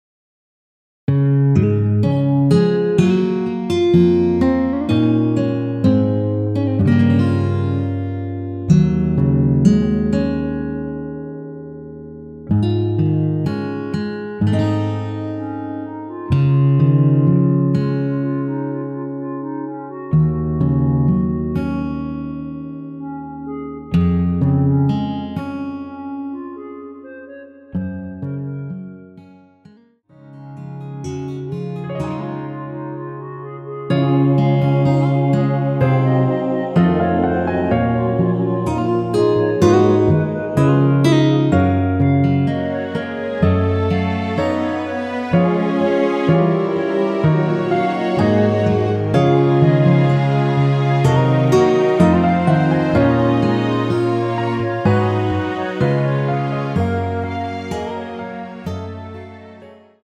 원키에서(-2)내린 멜로디 포함된 MR입니다.
Db
앞부분30초, 뒷부분30초씩 편집해서 올려 드리고 있습니다.
중간에 음이 끈어지고 다시 나오는 이유는